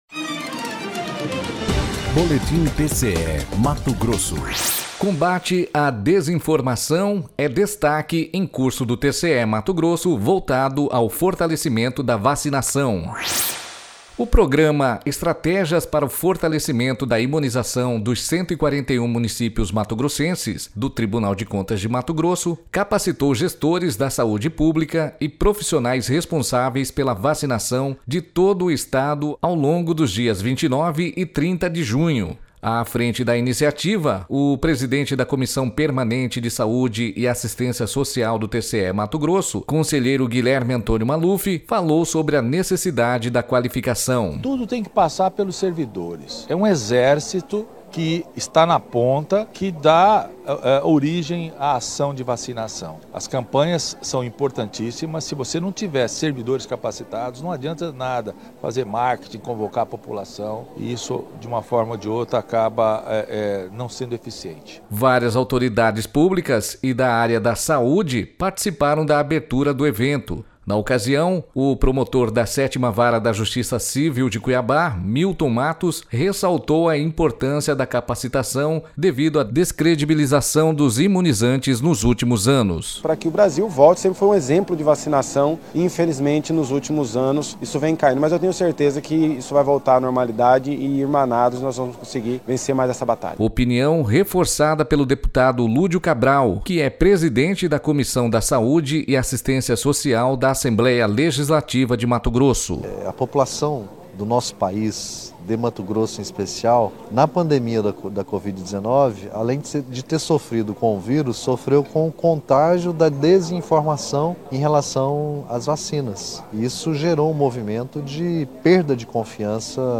Sonora: Guilherme Antonio Maluf – conselheiro presidente da CPSA do TCE-MT
Sonora: Milton Mattos - promotor da 7ª Vara da justiça civil de Cuiabá
Sonora: Lúdio Cabral - presidente da Comissão da Saúde e Assistência Social da AL-MT
Sonora: Gilberto Figueiredo - secretário de estado de saúde de MT